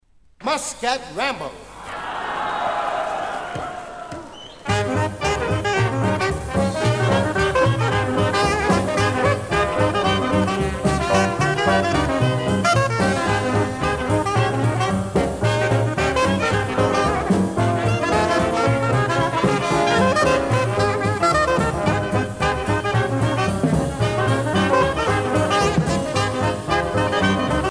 danse : charleston